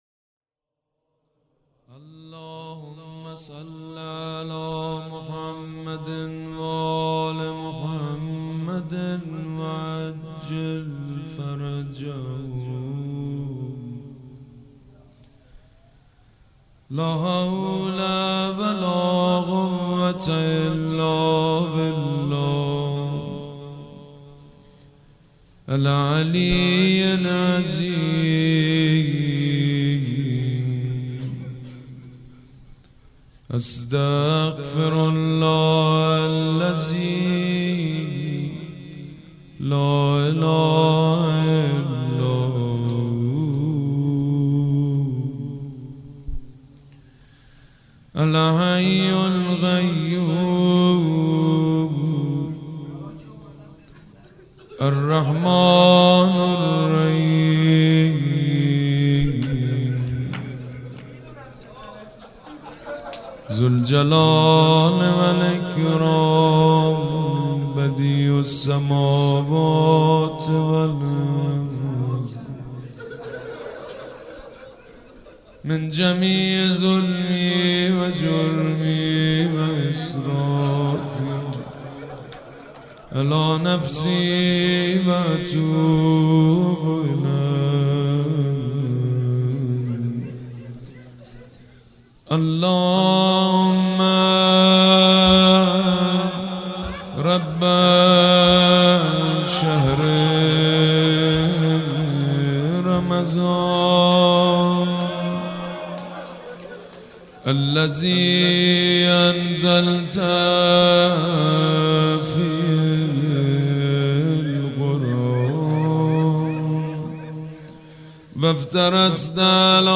سبک اثــر مناجات
مداح حاج سید مجید بنی فاطمه